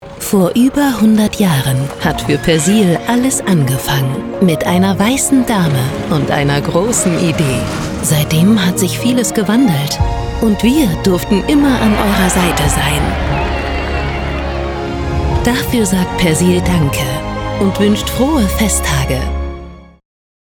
hell, fein, zart, sehr variabel, markant, plakativ
Jung (18-30)
Norddeutsch